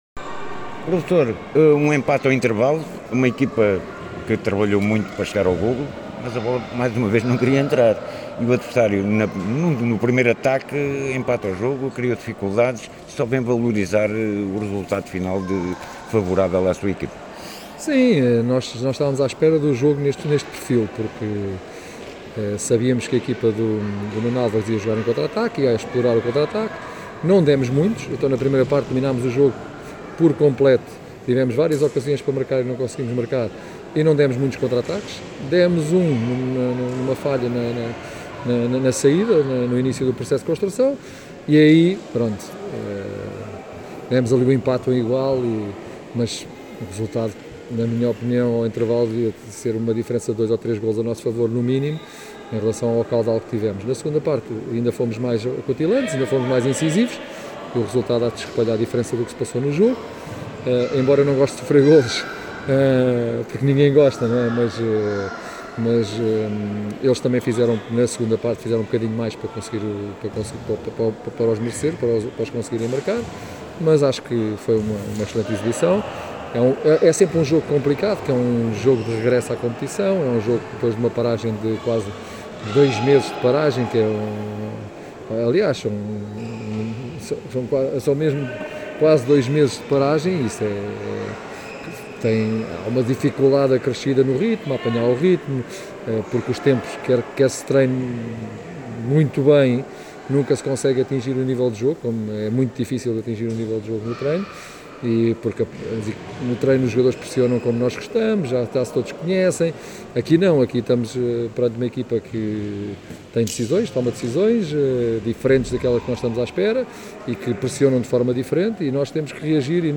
No final ouvimos os responsáveis técnicos de ambas as equipas: